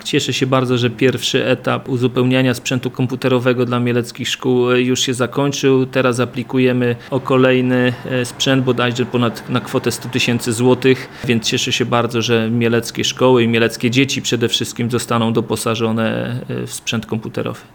Mówi prezydent Mielca, Jacek Wiśniewski.